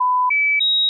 If you want to make recordings of multiple trials segmented by a trial-onset beep, you can use
markersignal.wav